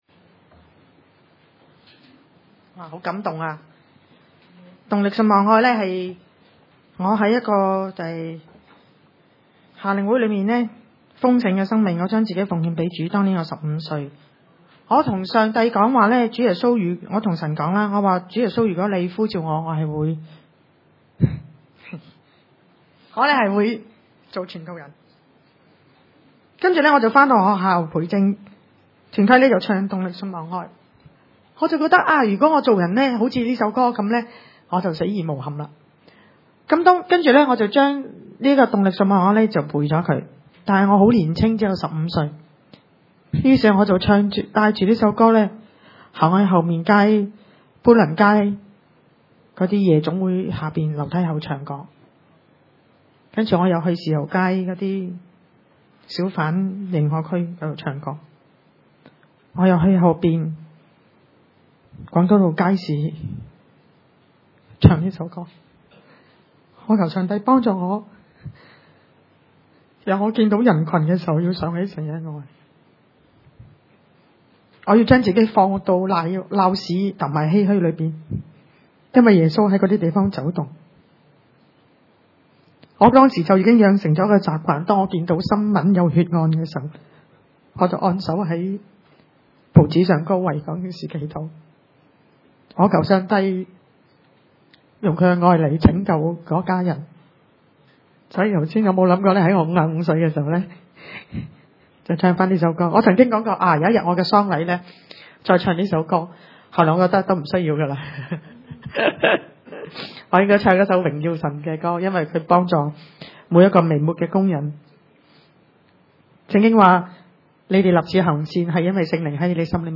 路加福音 11:1－13 崇拜類別: 主日午堂崇拜 1 耶穌在一個地方禱告；禱告完了，有個門徒對他說：「求主教導我們禱告，像 約翰 教導他的門徒。」